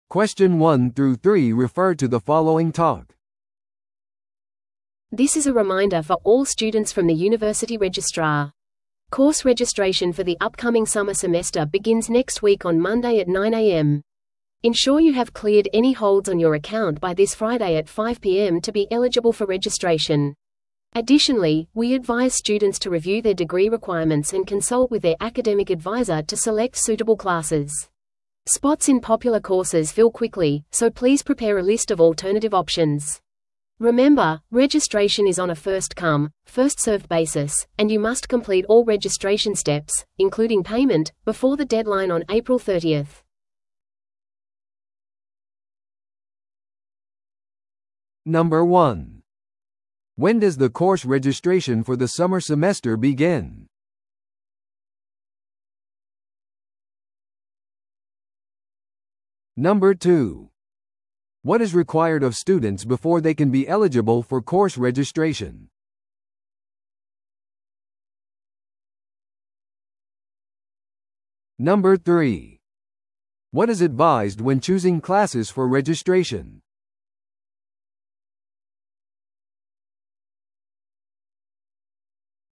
TOEICⓇ対策 Part 4｜授業登録のリマインダー – 音声付き No.118